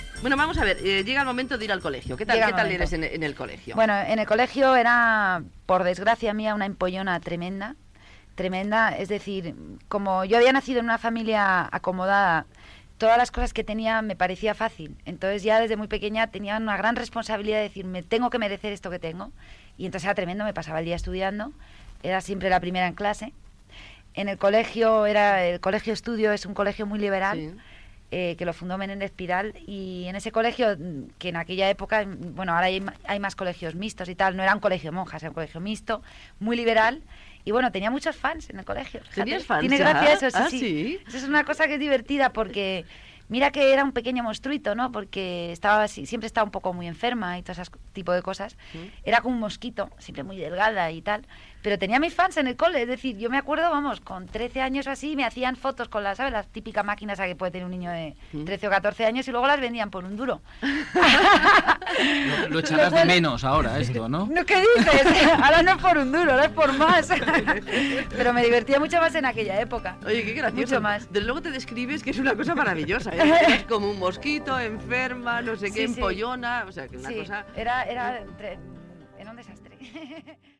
Entrevista a l'actriu Ana Obregón que recorda el seu pas per l'escola